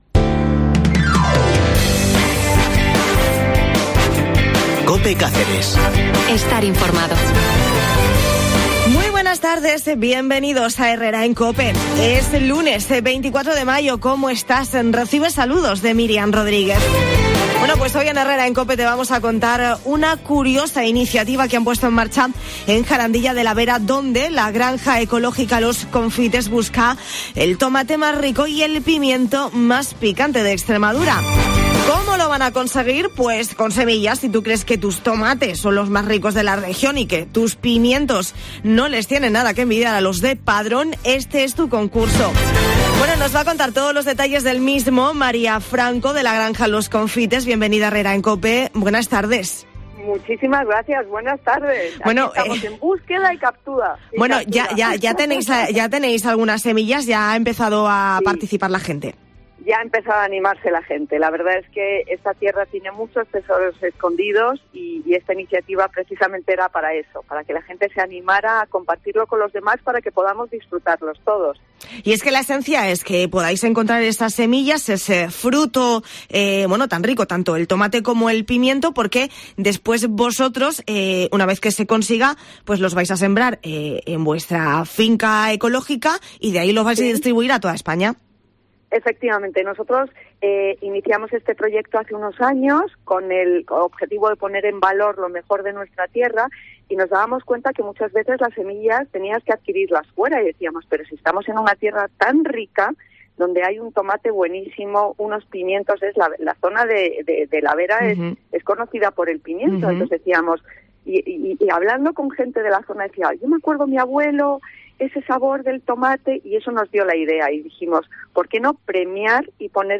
En Herrera en Cope Cáceres nos cuenta todos los detalles del mismo